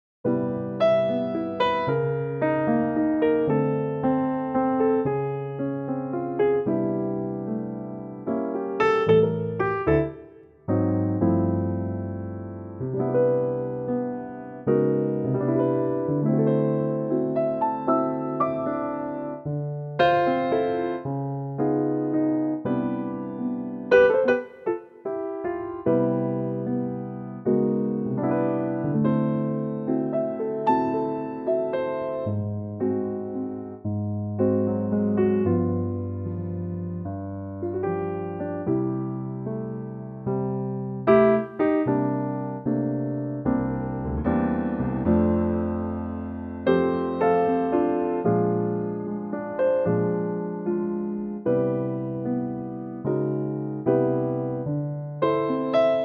key Bb
4 bar intro and vocal in at 14 seconds
in a really lovely trio plus strings arrangement.